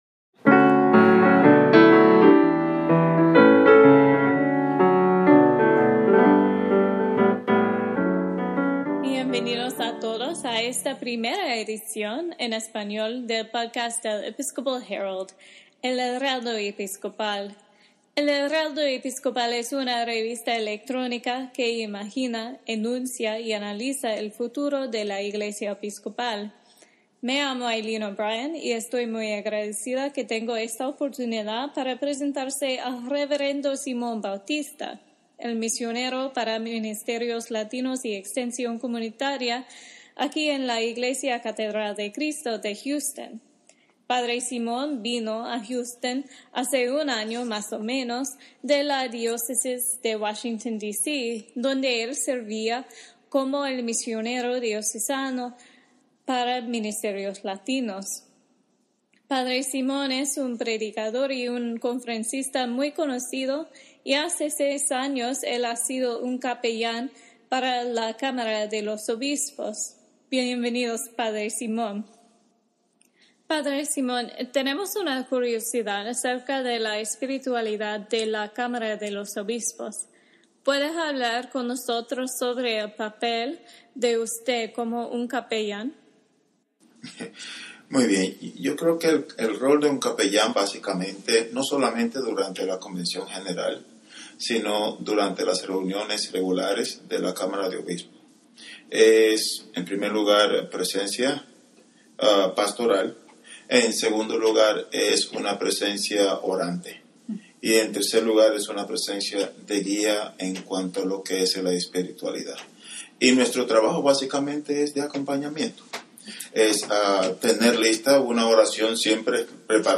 Podcast del Heraldo Episcopal: Una Conversación